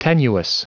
Prononciation du mot tenuous en anglais (fichier audio)
Prononciation du mot : tenuous